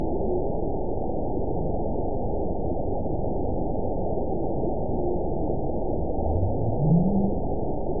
event 922359 date 12/30/24 time 02:32:34 GMT (11 months ago) score 9.35 location TSS-AB02 detected by nrw target species NRW annotations +NRW Spectrogram: Frequency (kHz) vs. Time (s) audio not available .wav